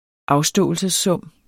afståelsessum substantiv, fælleskøn Bøjning -men, -mer, -merne Udtale [ ˈɑwˌsdɔˀəlsəsˌsɔmˀ ] Betydninger 1.